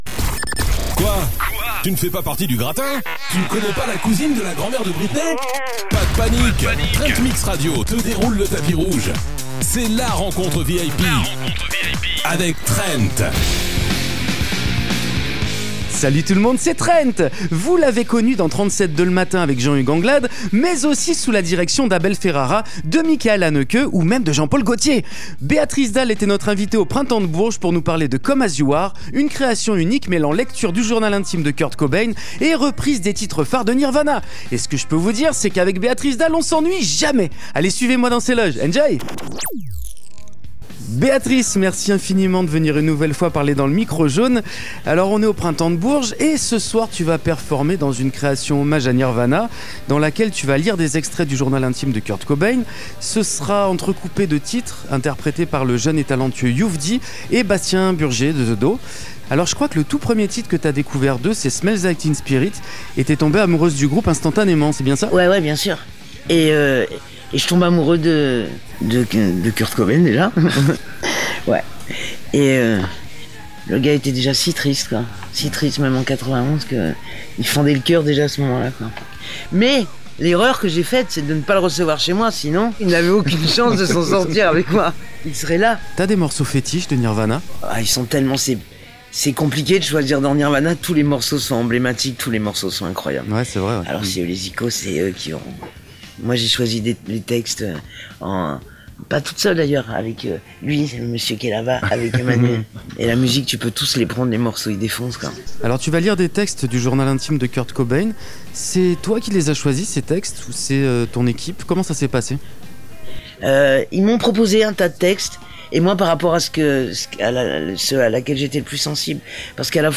Béatrice Dalle : l'interview !
Béatrice Dalle était notre invitée au Printemps de Bourges pour nous parler de « Come as you are », une création unique mêlant lecture du journal intime de Kurt Cobain et reprise des titres phares de Nirvana.